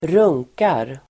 Uttal: [²r'ung:kar]
runkar.mp3